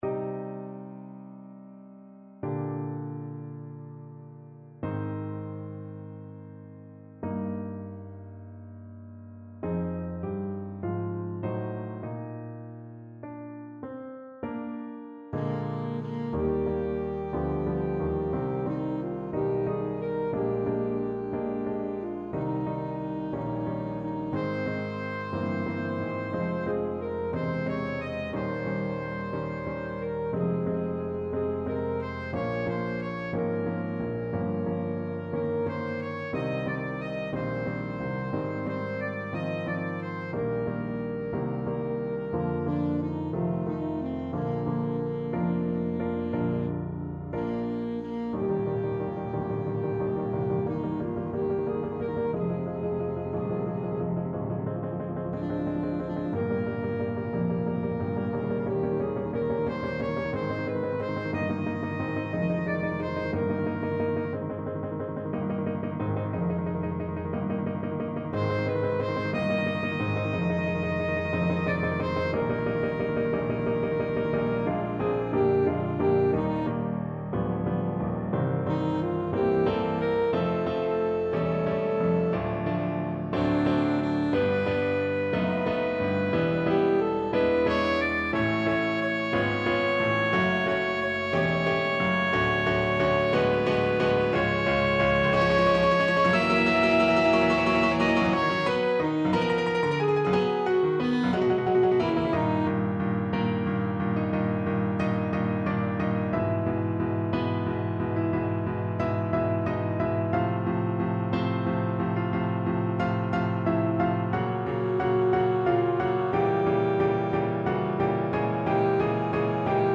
Alto Saxophone
4/4 (View more 4/4 Music)
Moderato assai
Classical (View more Classical Saxophone Music)